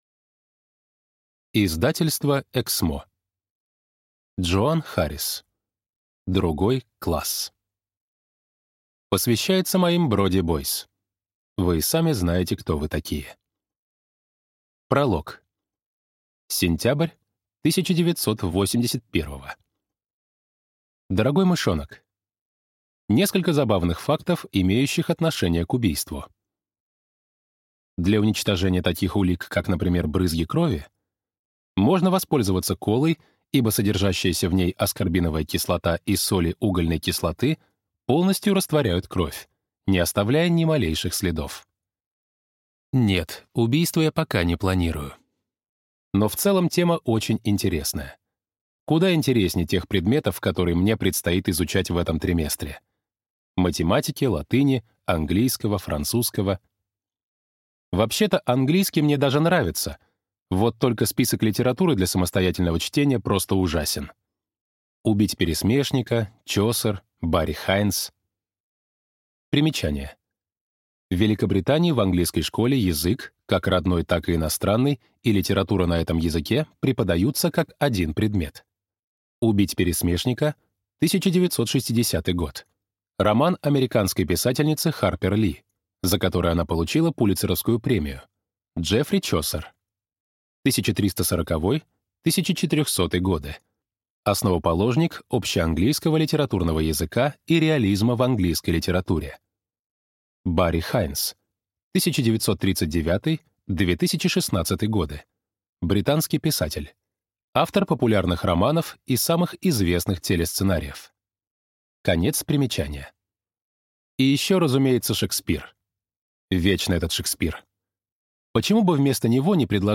Аудиокнига Другой класс | Библиотека аудиокниг